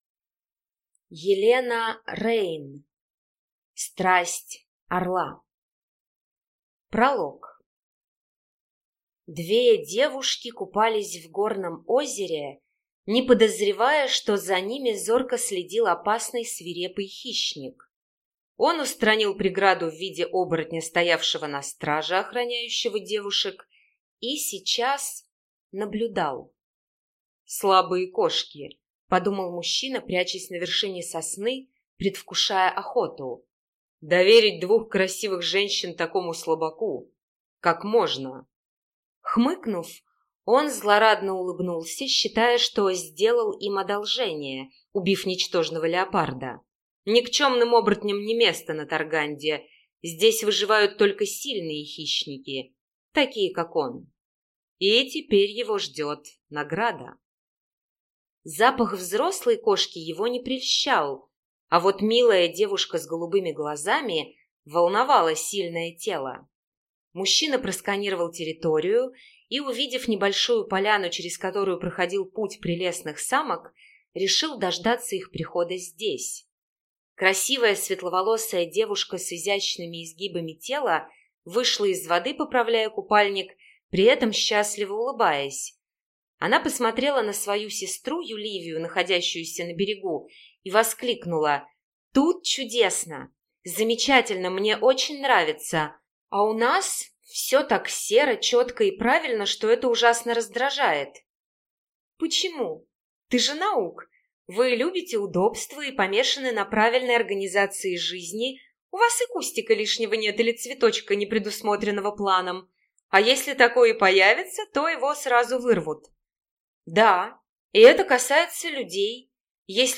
Аудиокнига Страсть орла | Библиотека аудиокниг